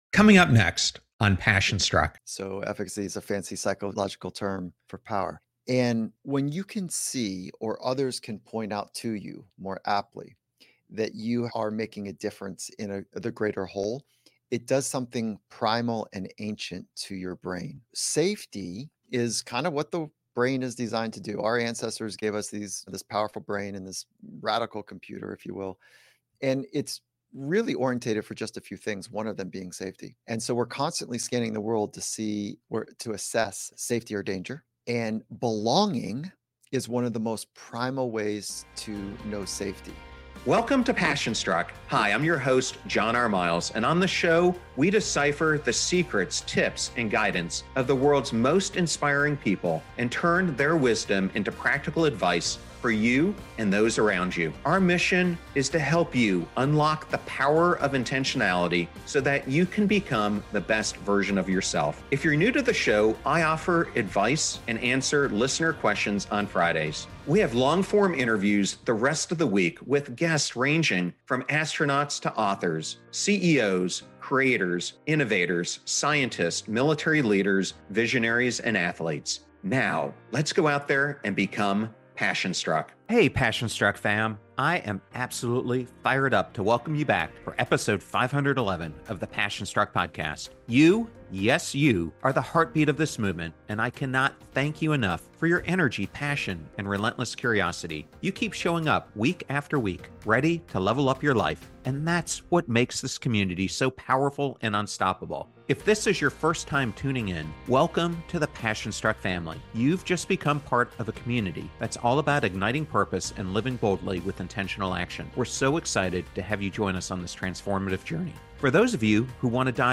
They dive deep into the psychology of high performance, the importance of creating a sense of safety and belonging, and how overcoming fear and self-doubt can unlock your true potential. Get ready for a compelling conversation packed with actionable insights for mastering your mindset and achieving the science of success.